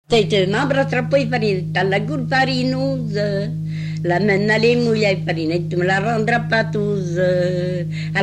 Divertissements d'adultes - Couplets à danser
branle : courante, maraîchine
Pièce musicale inédite